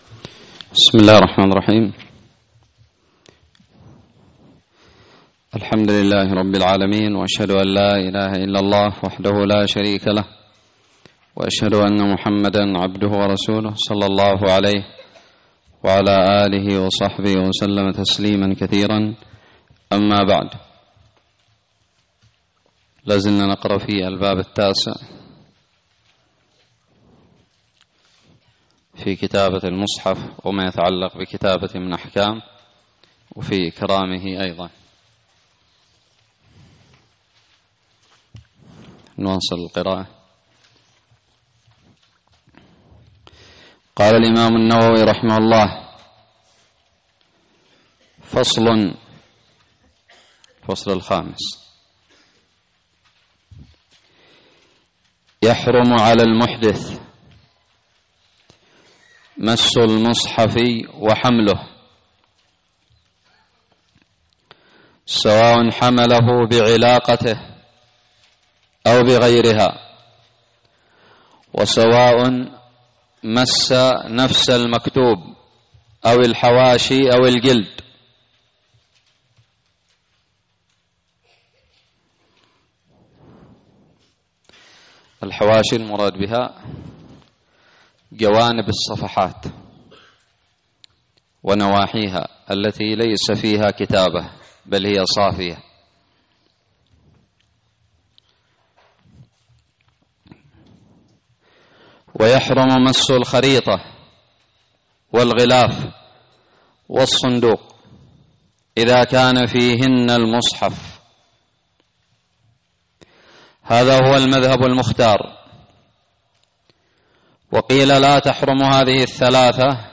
ألقيت بدار الحديث السلفية للعلوم الشرعية بالضالع